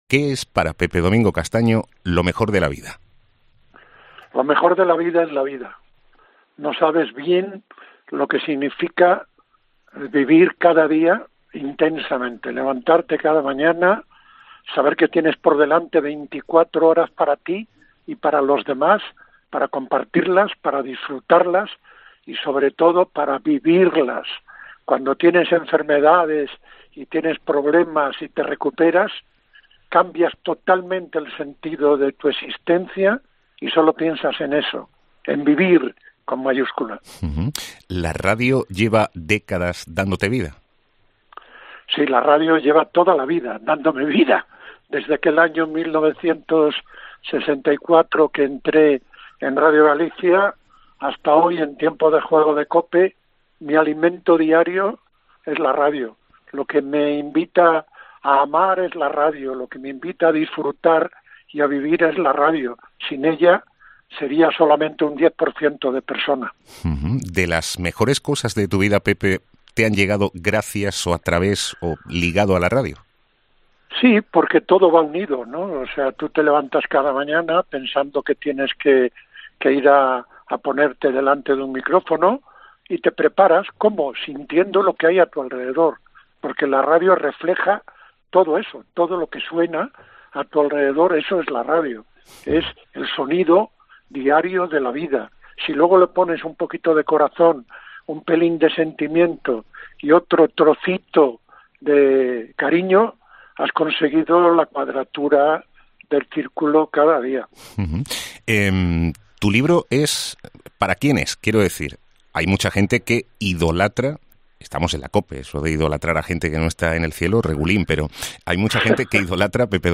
ctv-zpi-entrevista pepe domingo